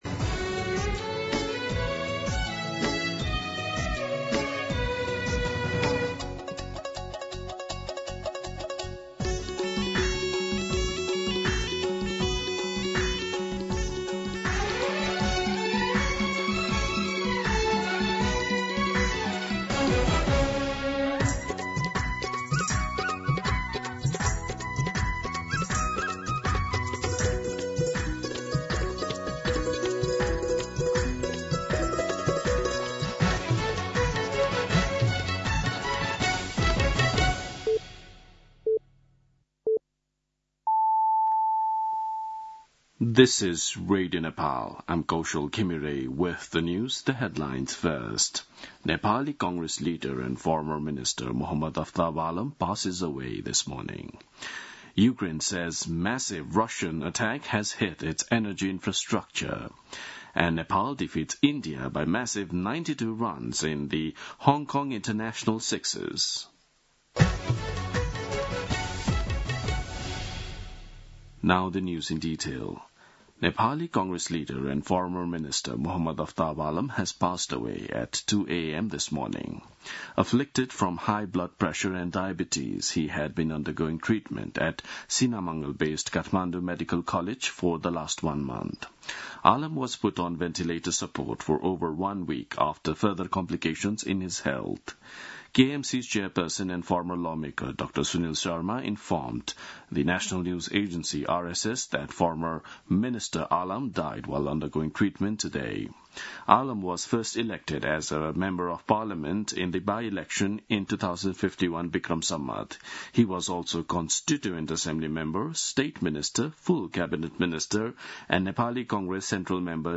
दिउँसो २ बजेको अङ्ग्रेजी समाचार : २२ कार्तिक , २०८२
2-pm-English-News-2.mp3